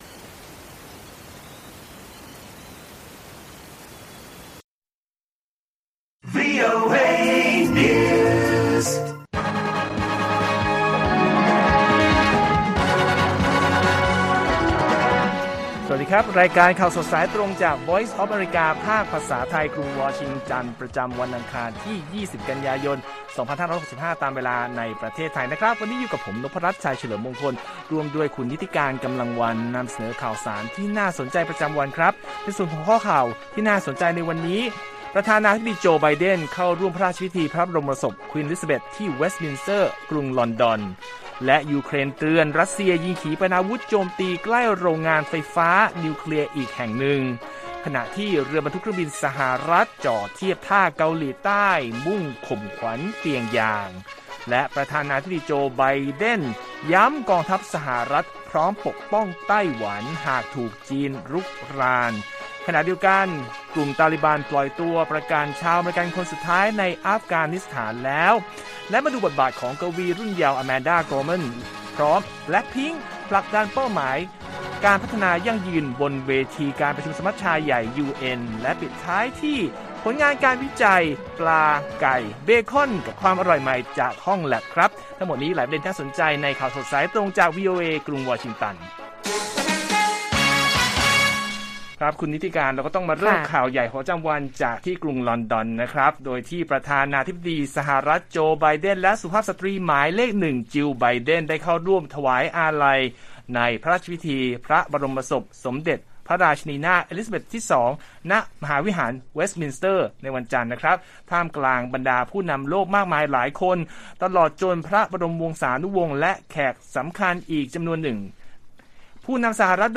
ข่าวสดสายตรงจากวีโอเอ ไทย อังคาร 20 ก.ย.65